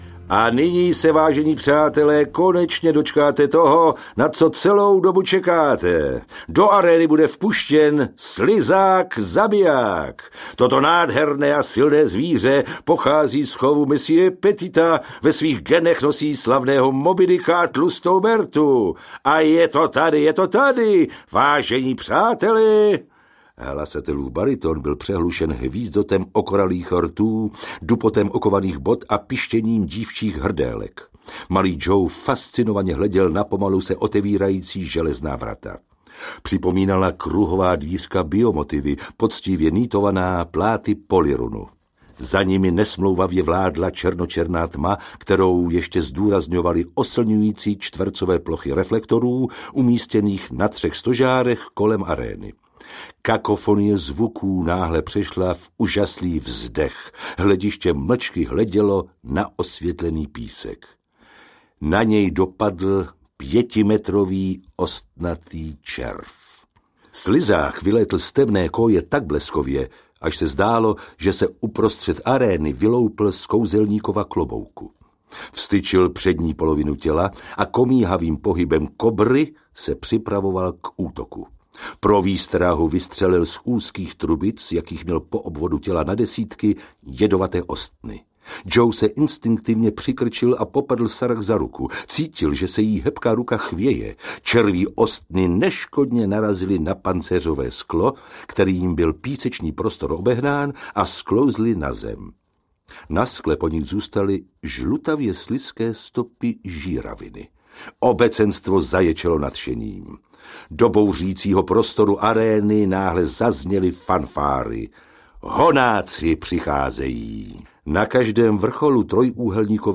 Hvězdní Honáci audiokniha
Ukázka z knihy
hvezdni-honaci-audiokniha